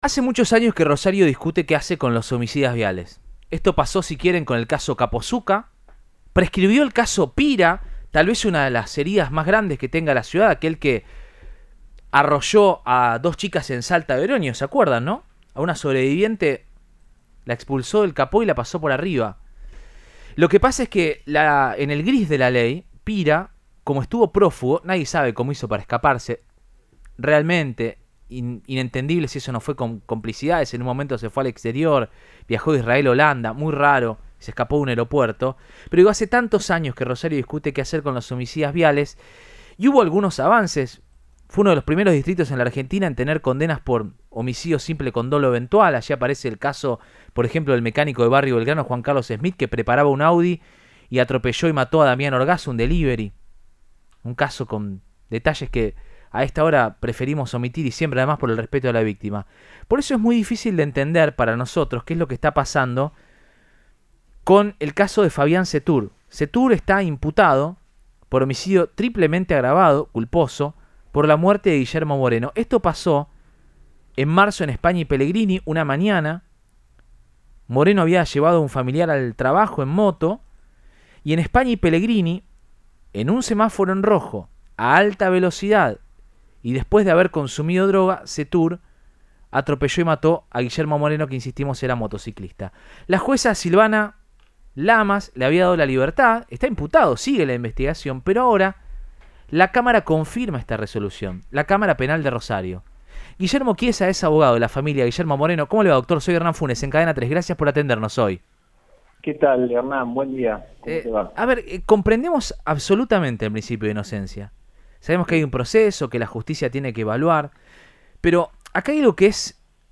dialogó con Radioinforme 3 de Cadena 3 Rosario y brindó un panorama de cómo sigue la causa.